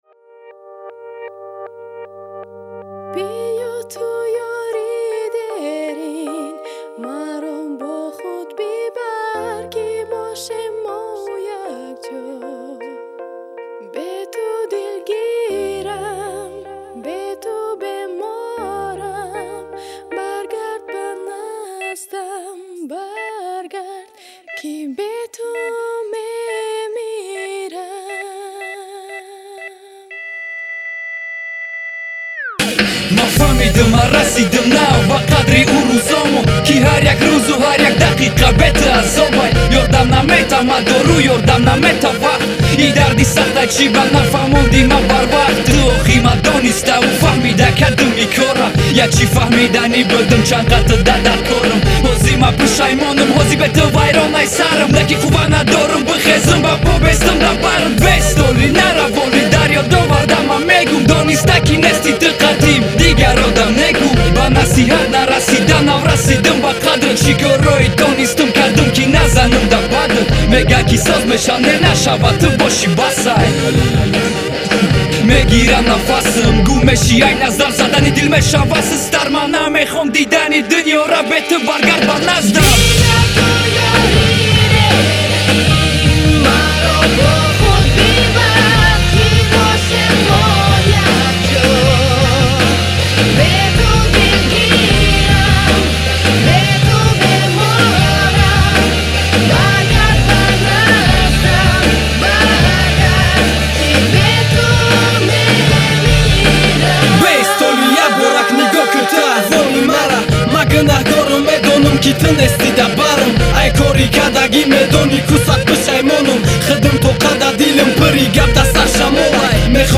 Rap,hip-hop